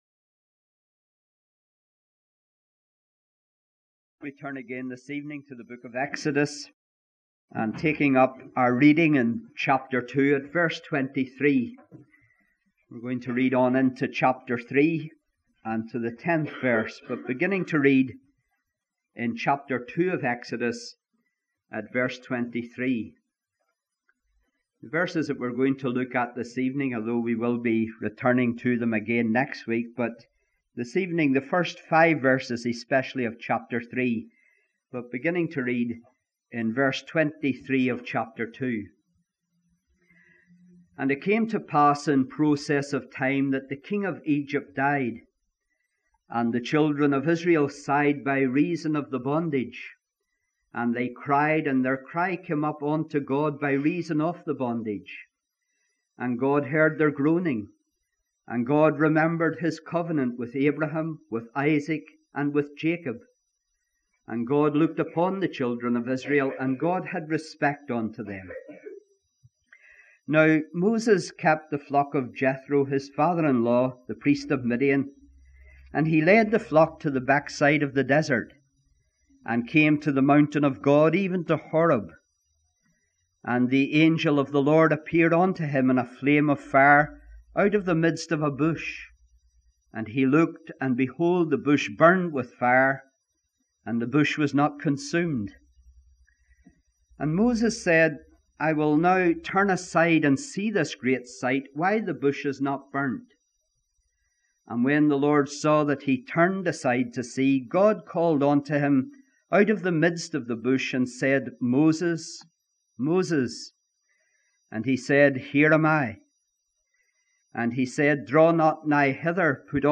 He preached these sermons from the Book of Exodus between 1982 and 1984.